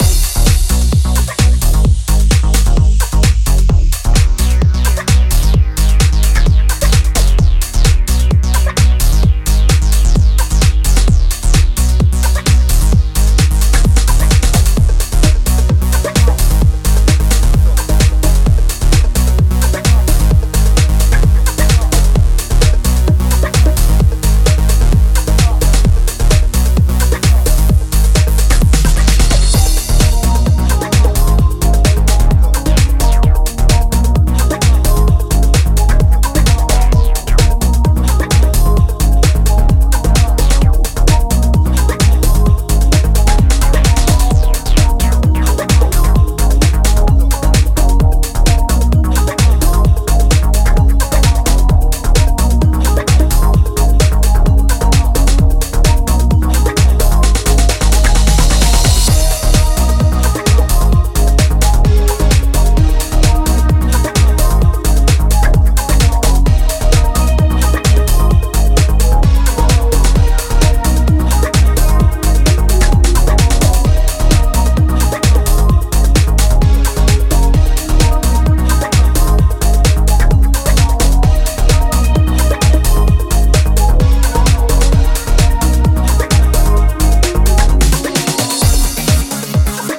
パワフルなボトム上でサイファイなシンセレイヤーとアシッドが乱反射する